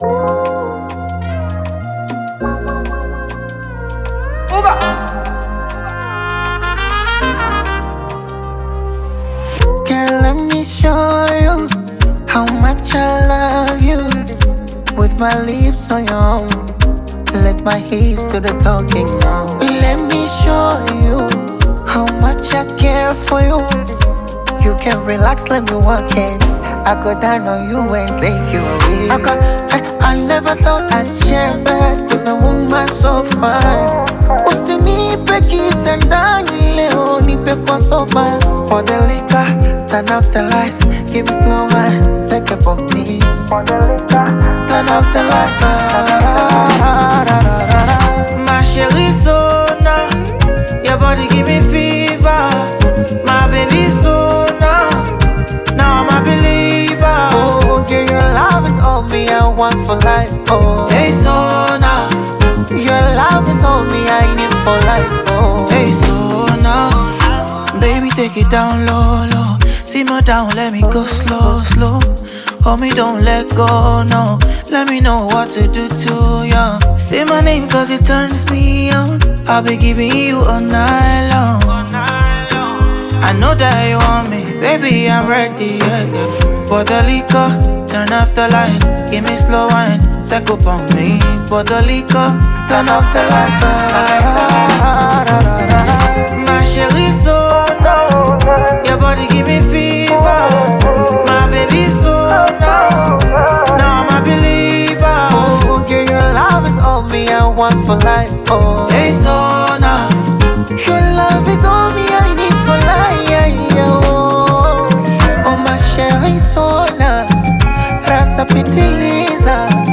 multi-talented Nigerian singer
African Music